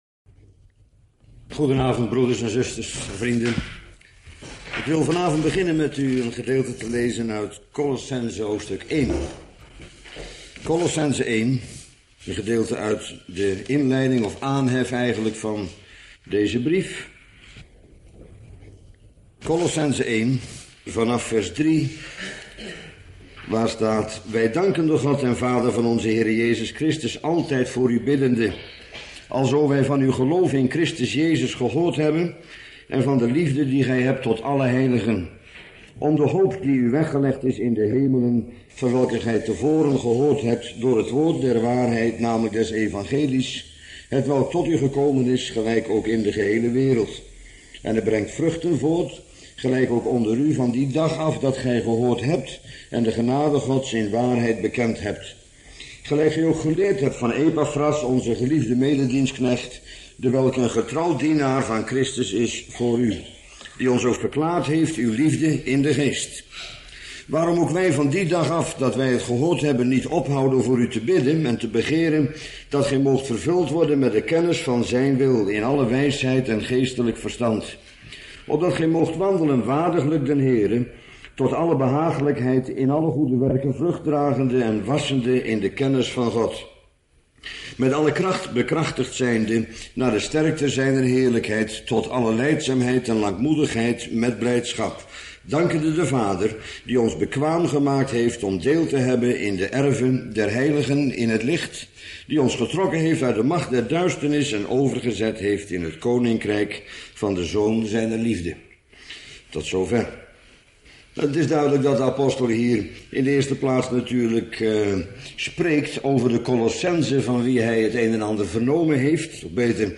Bijbelstudie